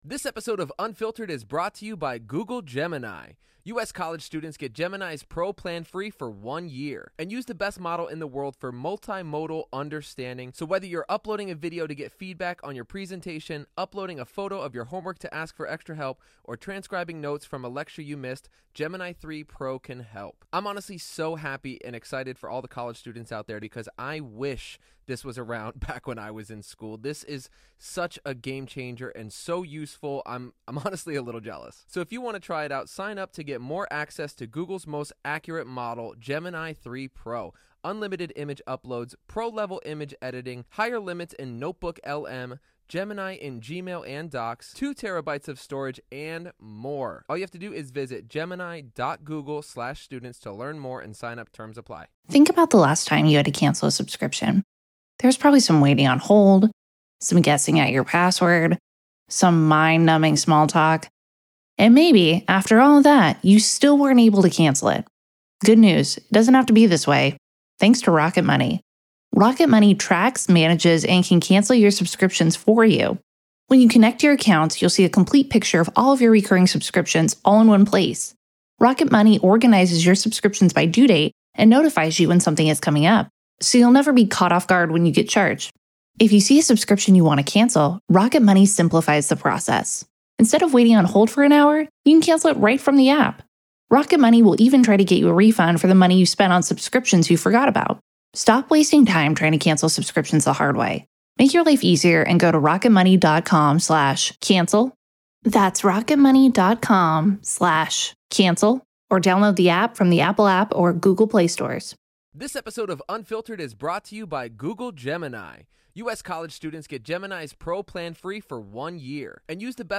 From parents’ misplaced trust in celebrities to the manipulation of those in close proximity, the discussion digs into the troubling patterns of power and control.